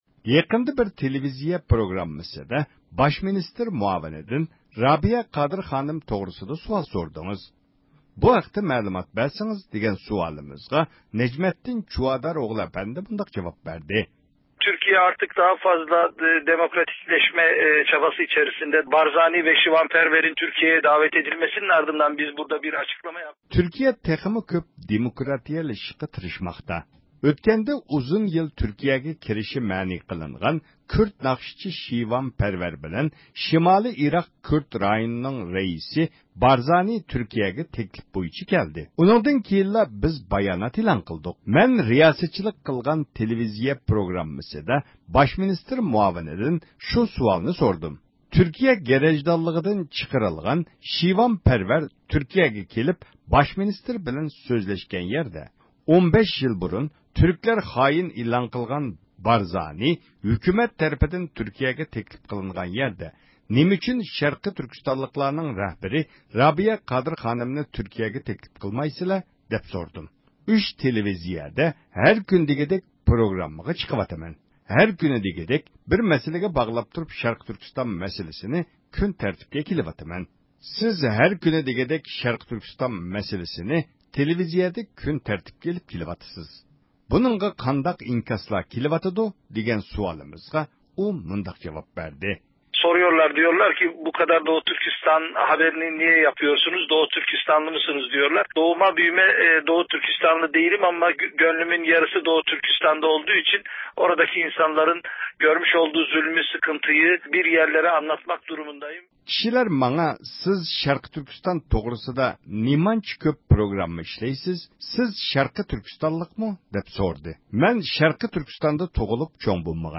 تۆۋەندە ئۇنىڭ بىلەن ئېلىپ بارغان سۆھبىتىمىز دىققىتىڭلاردا بولسۇن.